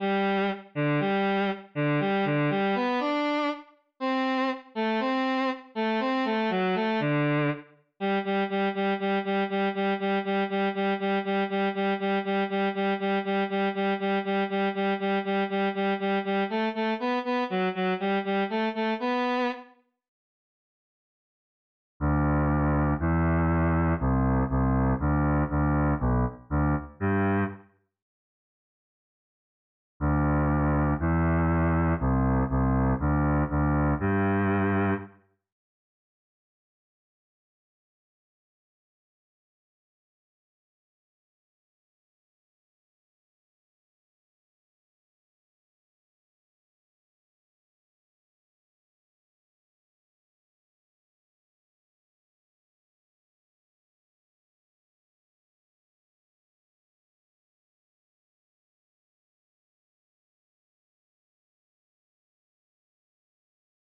eine-kleine-bass-new.wav